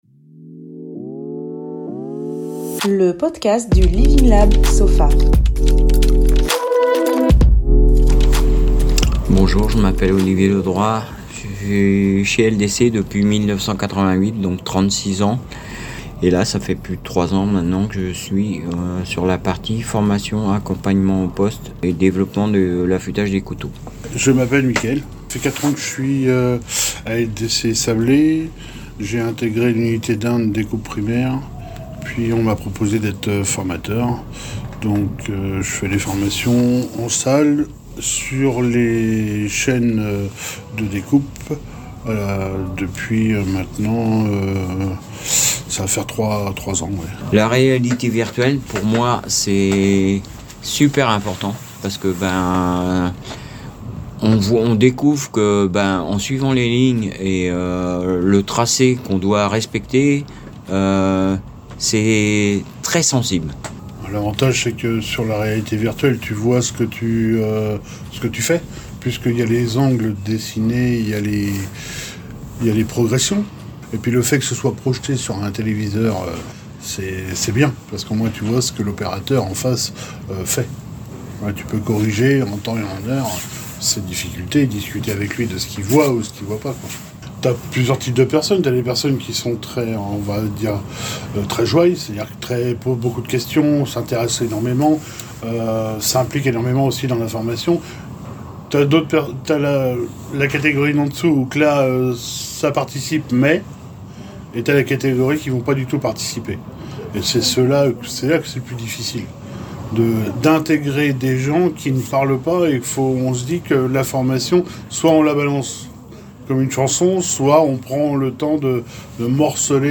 Ecoutez maintenant le retour des deux formateurs suite à cette expérimentation pédagogique.